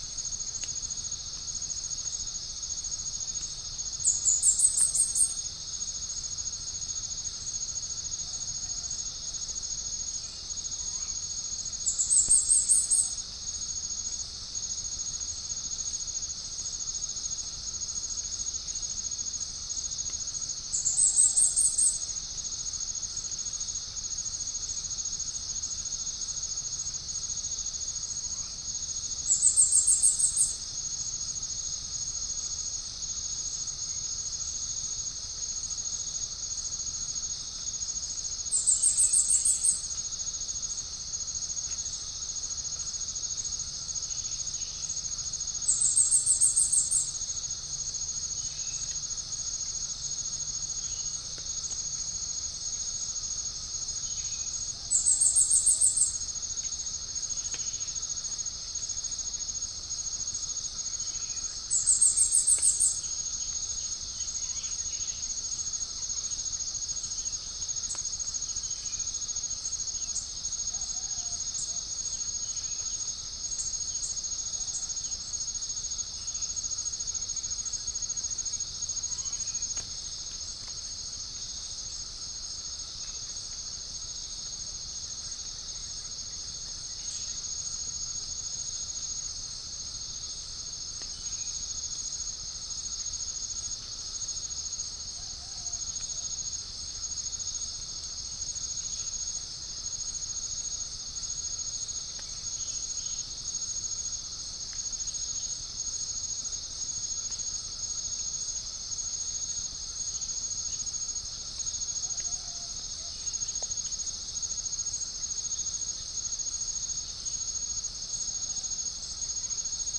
Gallus gallus
Spilopelia chinensis
Pycnonotus goiavier
unknown bird
Pycnonotus aurigaster
Todiramphus chloris
Dicaeum trigonostigma